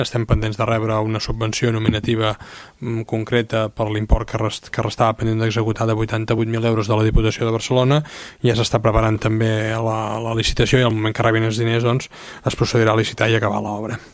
En l’última sessió plenària, l’alcalde Marc Buch va indicar a preguntes de l’oposició en quin punt es troba el desenvolupament de les obres.